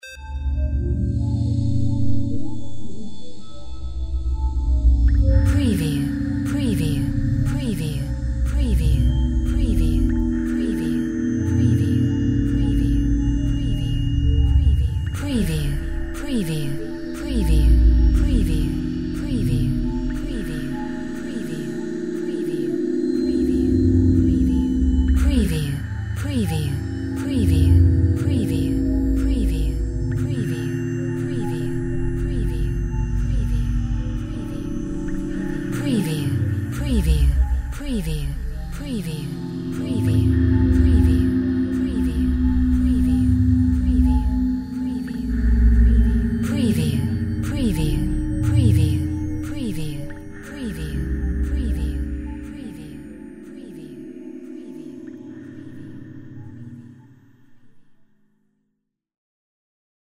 Drone Riveroftones Drip FX02
Modulating pitches and tones. With a hint of LFe and drip effects.
Stereo sound effect - Wav.16 bit/44.1 KHz and Mp3 128 Kbps
previewDRONE_RIVEROFTONES_DRIP_FX_WBSD02.mp3